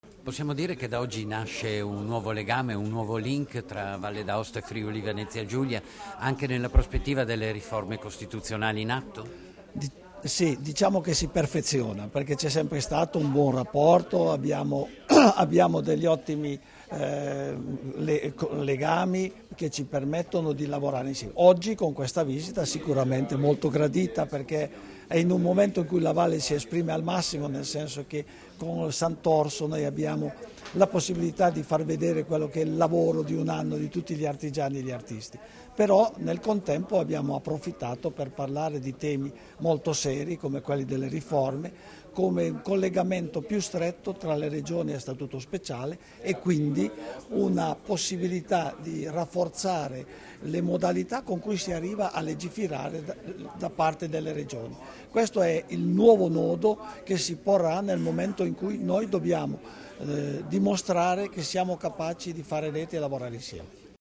Dichiarazioni di Augusto Rollandin (Formato MP3) [1024KB]
a margine dell'incontro con la presidente del Friuli Venezia Giulia Debora Serracchiani, rilasciate ad Aosta il 30 gennaio 2016